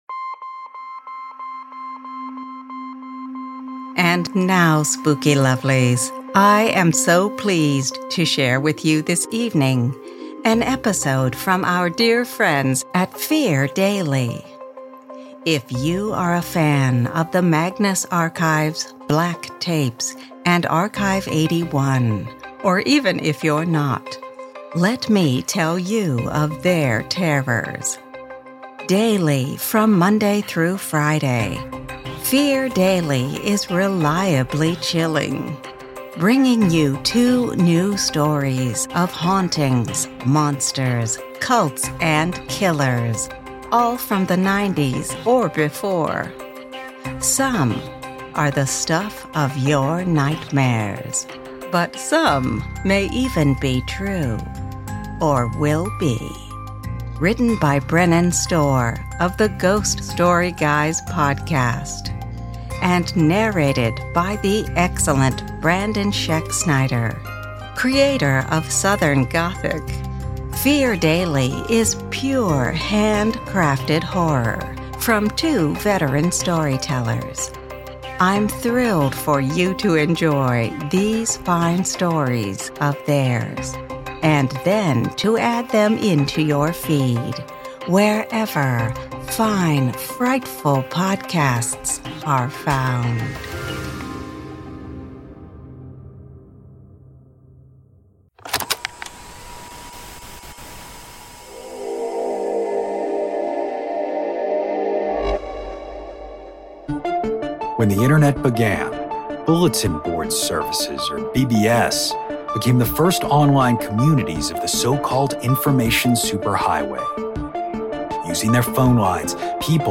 The Midnight Library is proud to present for your listening pleasure, Fear Daily, a deeply atmospheric show that lures you into the unknown to unsettle you with the uncanny. Fear Daily takes you into the shadows of the past, unearthing the 1990's most terrifying tales of monsters, madness, and life after death.